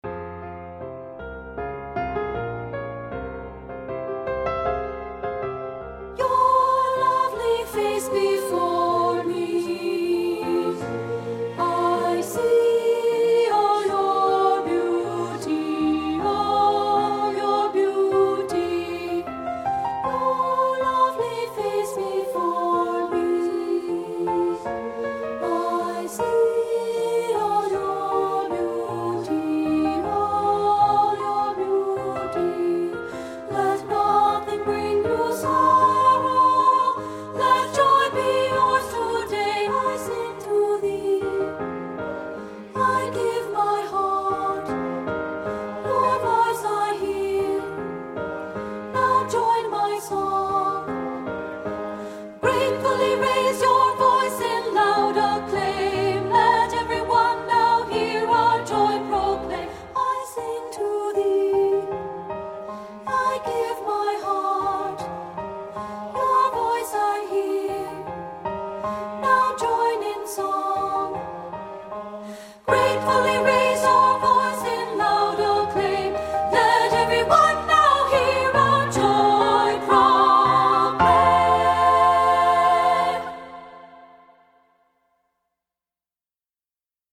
Voicing: SAB a cappella